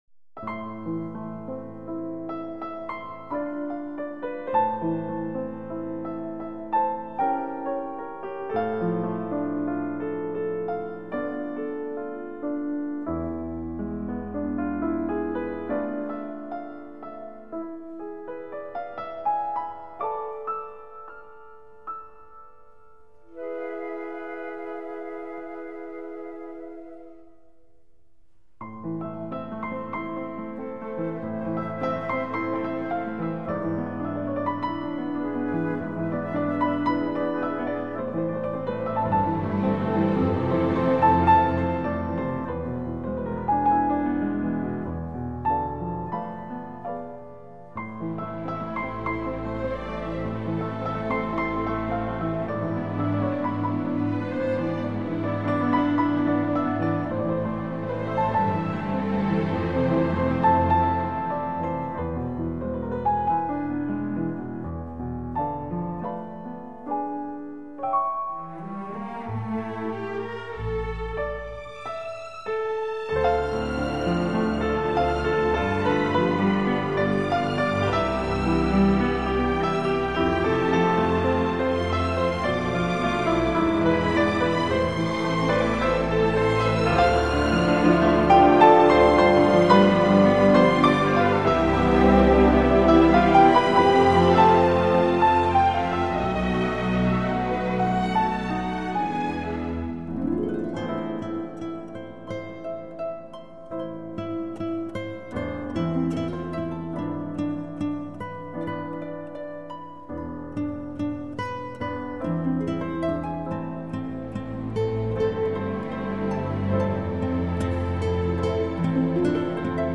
德国版 无压缩音乐 零距离聆听高密度声音层次